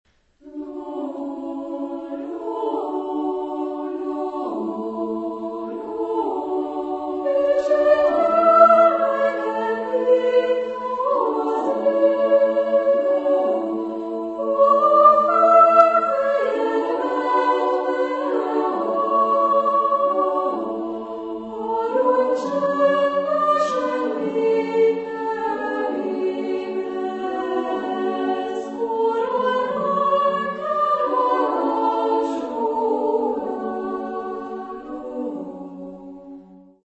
Epoque: 20th century
Genre-Style-Form: Lullaby
Mood of the piece: moderate
Type of Choir: SSA  (3 women voices )
Tonality: F sharp dorian